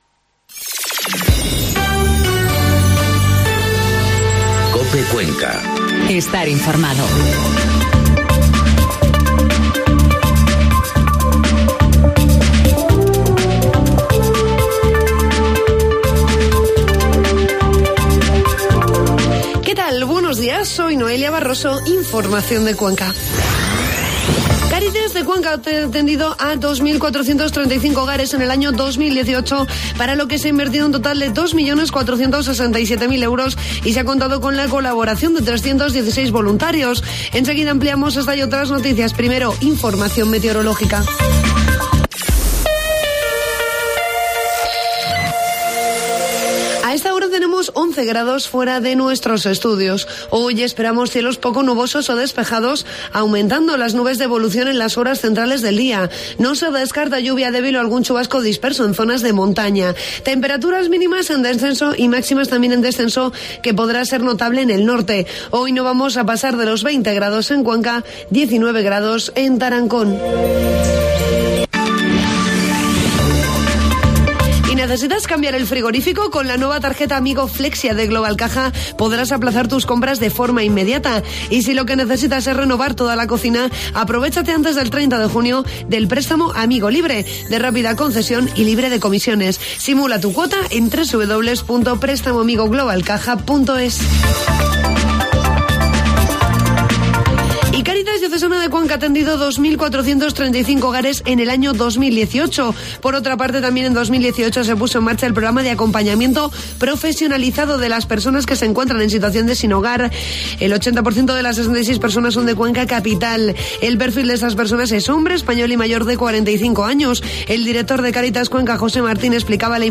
informativo matinal COPE Cuenca 11 de junio